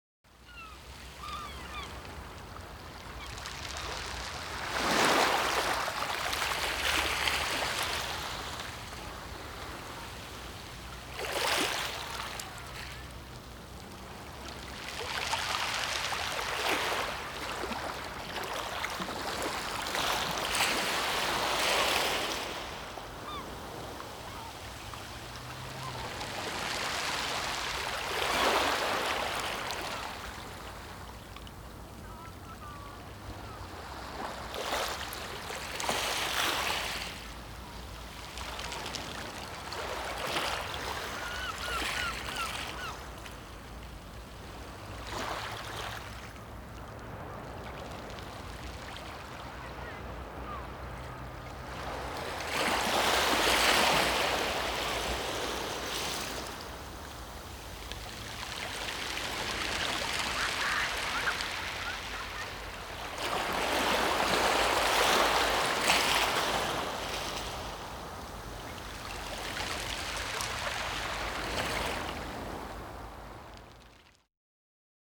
So klingt die Ostsee